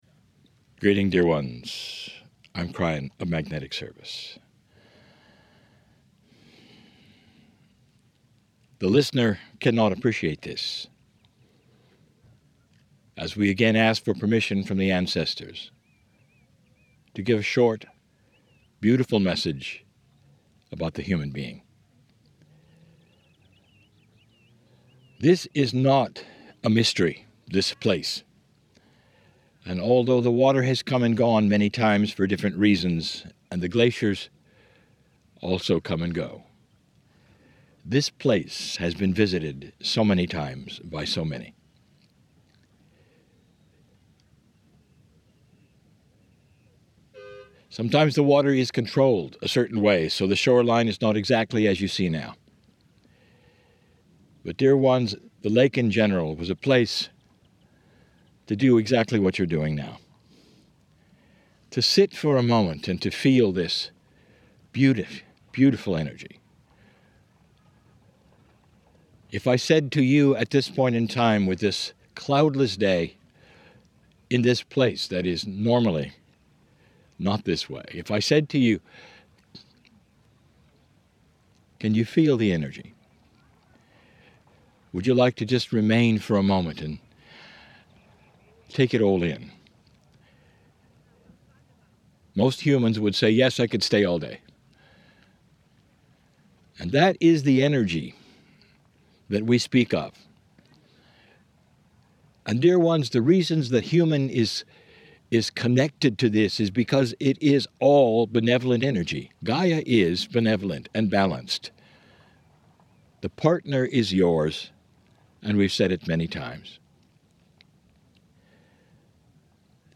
KRYON CHANNELLING Lake Tekapo